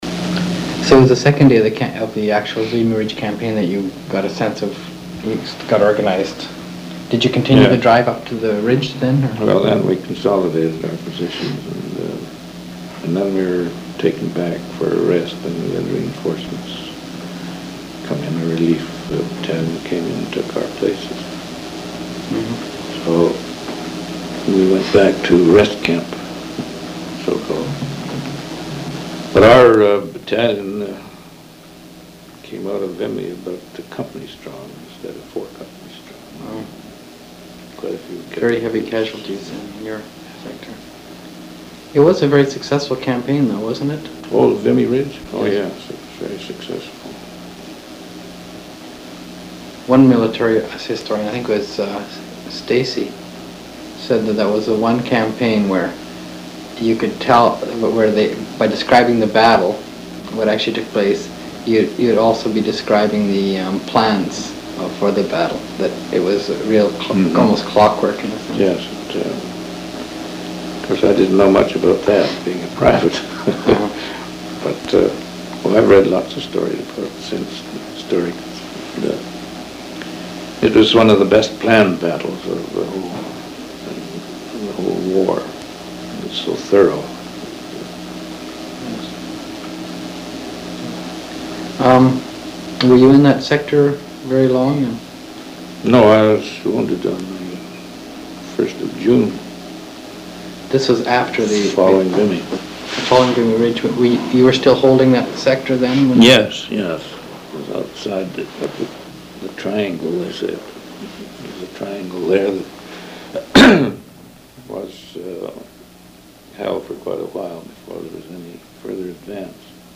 [Interview